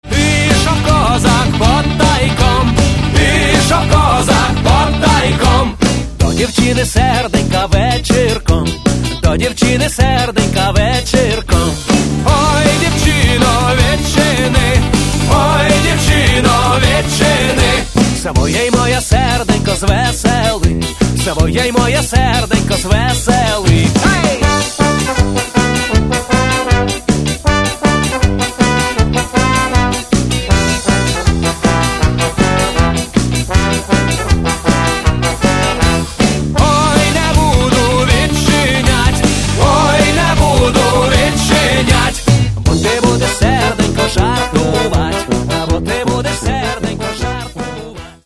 Catalogue -> Rock & Alternative -> Folk Rock